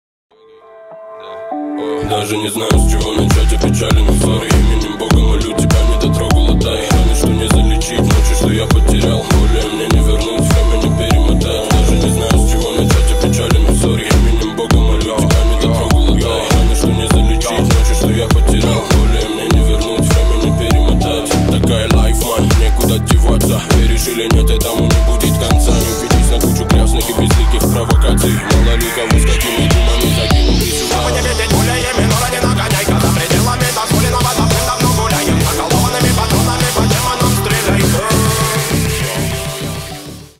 Ремикс
ритмичные # клубные